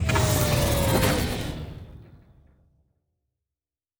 Sci-Fi Sounds / Doors and Portals / Door 9 Close.wav
Door 9 Close.wav